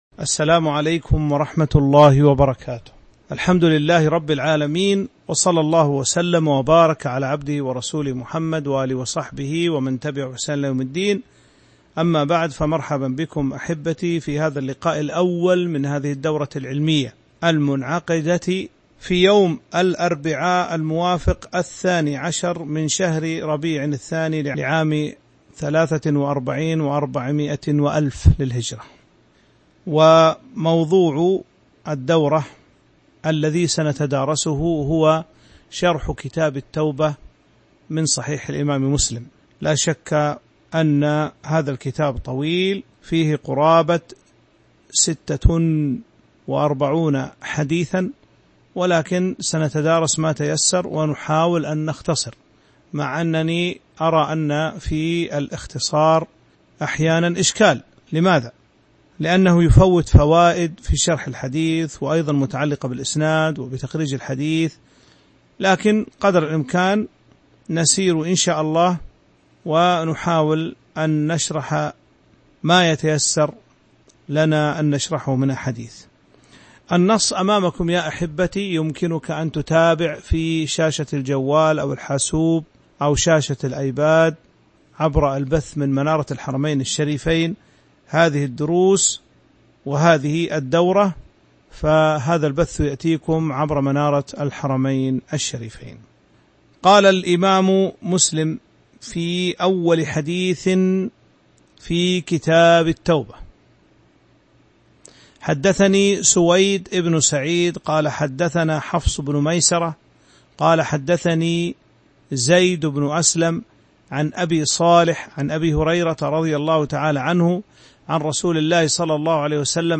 تاريخ النشر ١٢ ربيع الثاني ١٤٤٣ هـ المكان: المسجد النبوي الشيخ